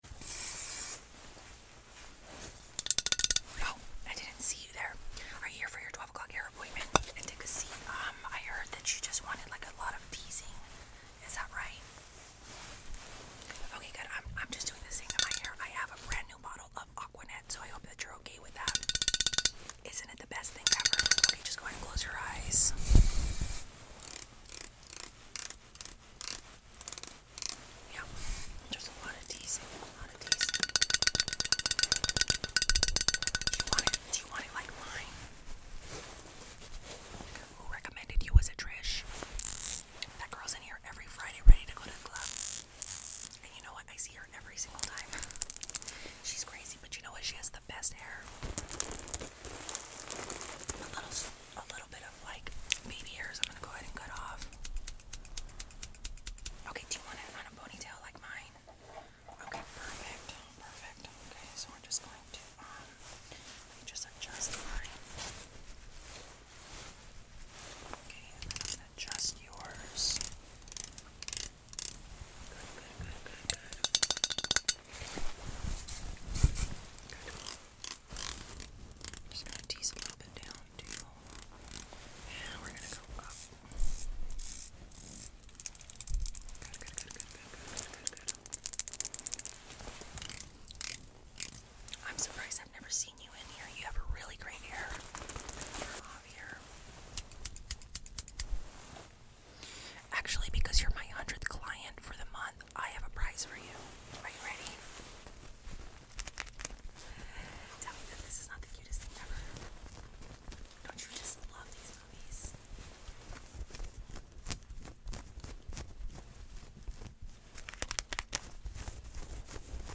Last Part Of 80s ASMR Sound Effects Free Download